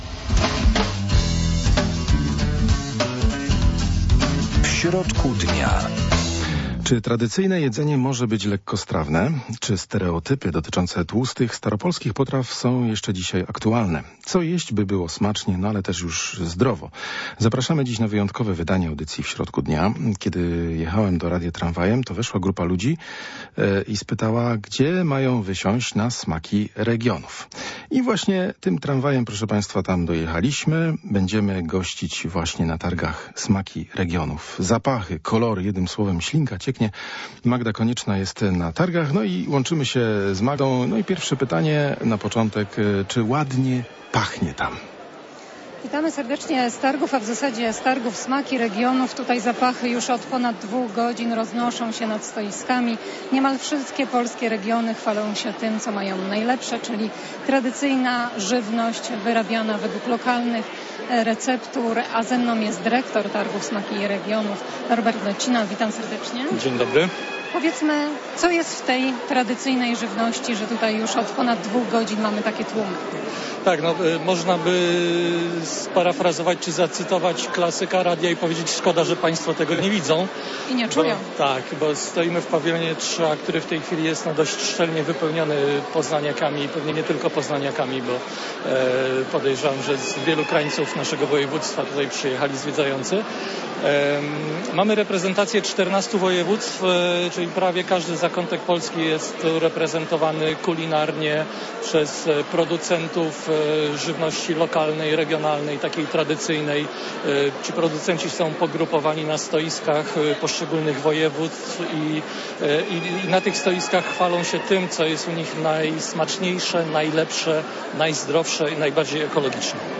Co jeść, by było smacznie ale też i zdrowo? Zapraszamy na wyjątkowe wydanie audycji W środku dnia o 12.15. Będziemy gościć na targach „Smaki regionów"